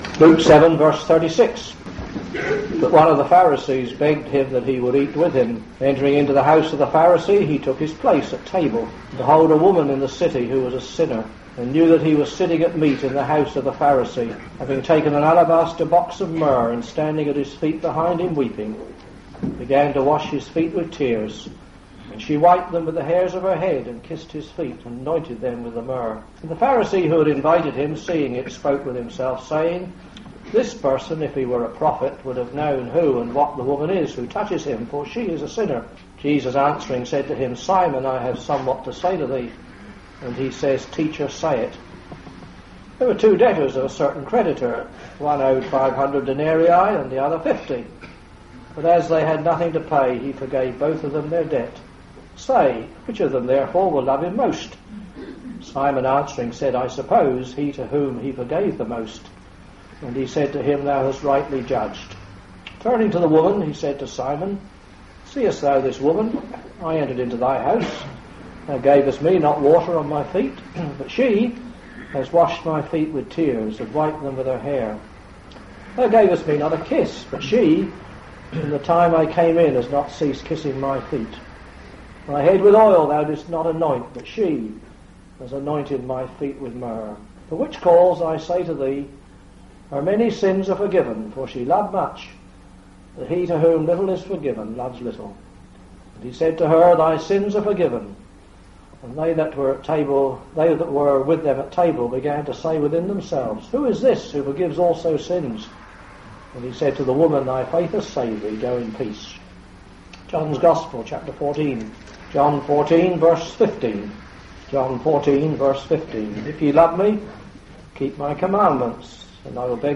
The Love of Christ will never fail us it remains the same forever. In the following address, you will hear what the scriptures say about Walking in the Love of Christ.